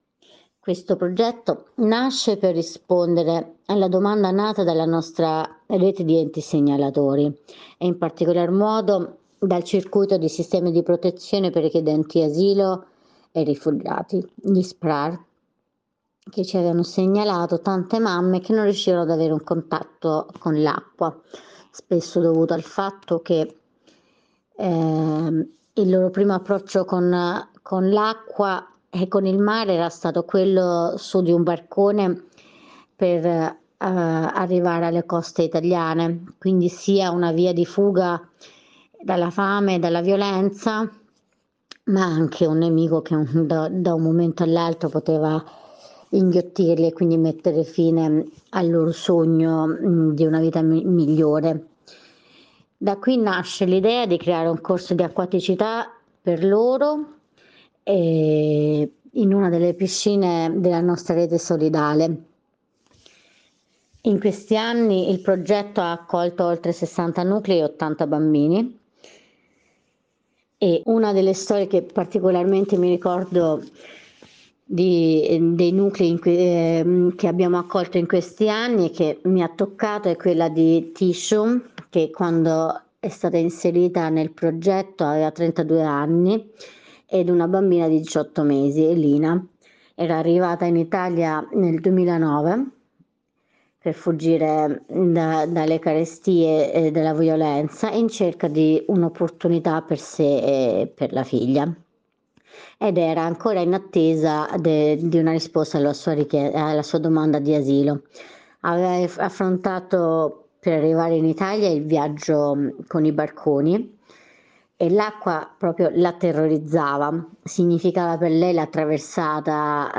A lei abbiamo chiesto di raccontarci una storia che l’abbia particolarmente segnata durante i suoi anni di lavoro con le mamme migranti e i loro piccoli.